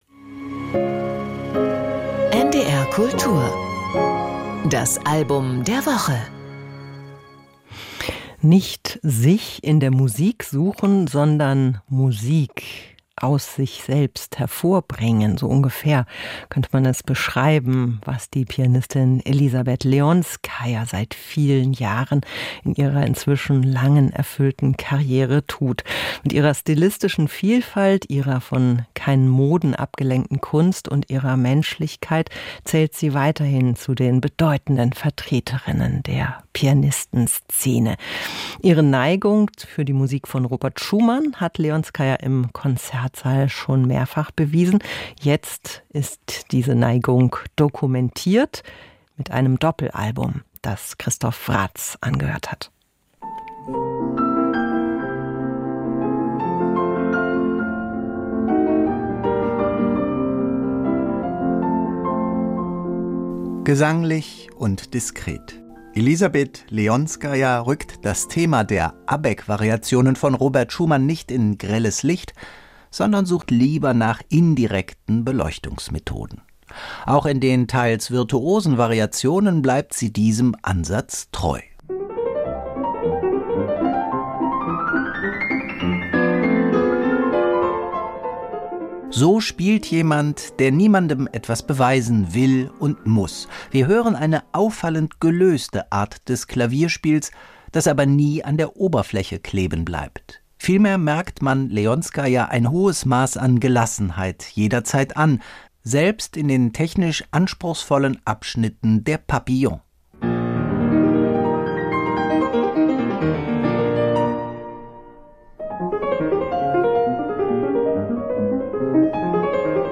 Feine Klangfarben, viel Gelassenheit, wenig Show: Die Pianistin